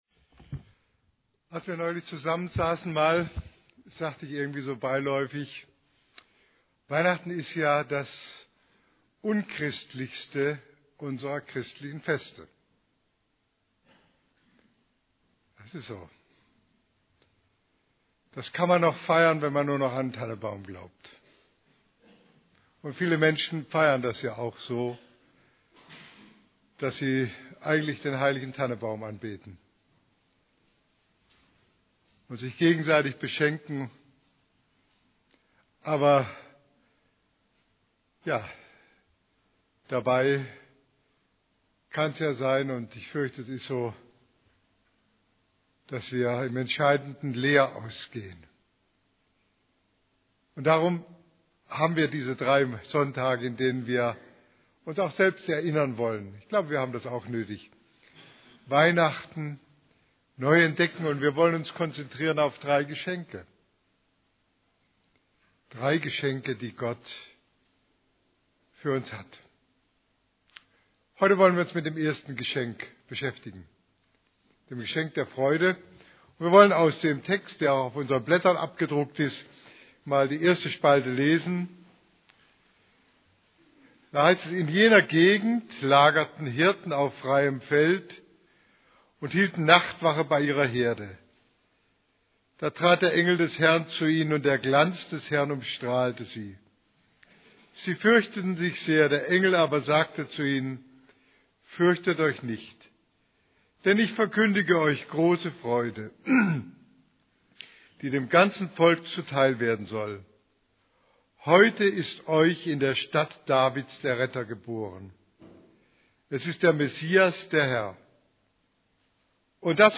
> Übersicht Predigten Warum Gott Weihnachten erfand: Das Geschenk der Freude Predigt vom 05.